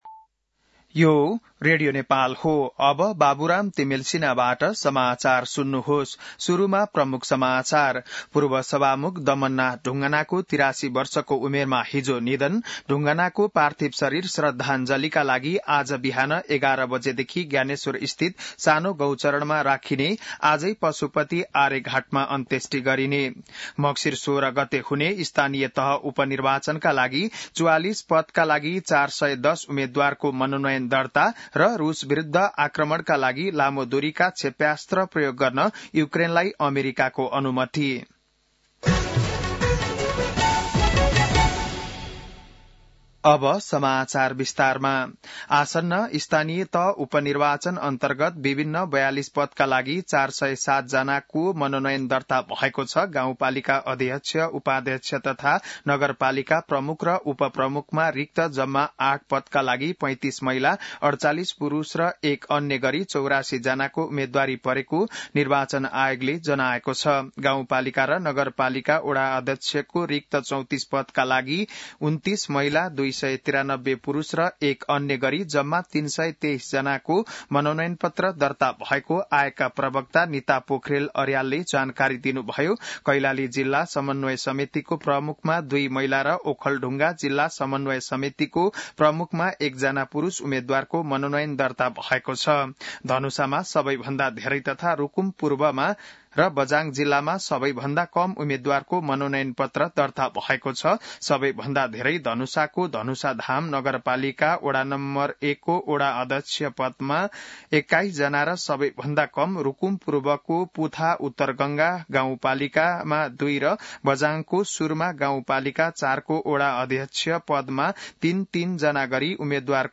बिहान ९ बजेको नेपाली समाचार : ४ मंसिर , २०८१